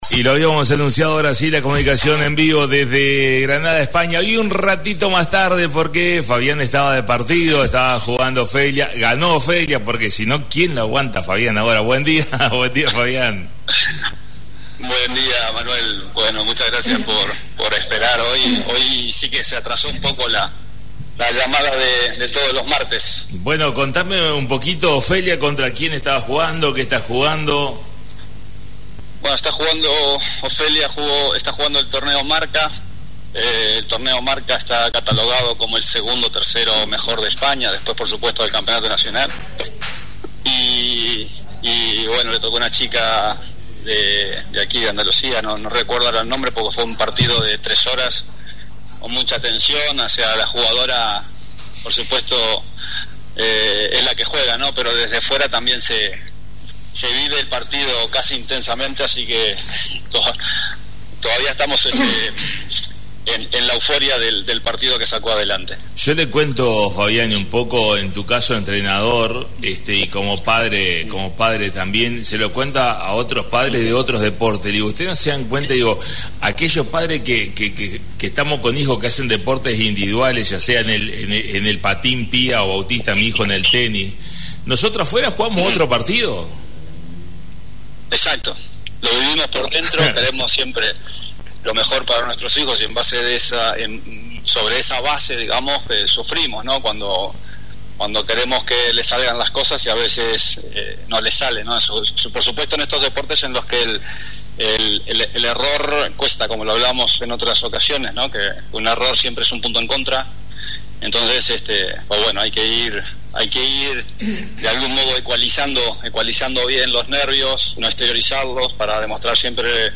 la comunicación en vivo